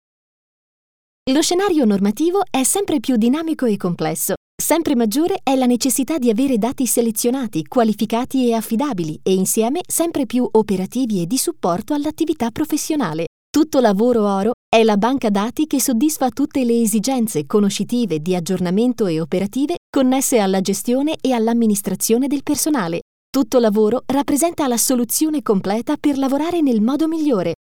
Professional home recording studio. My voice is friendly young and smiling.
Sprechprobe: Werbung (Muttersprache):
Italian professional female Voiceover Talent.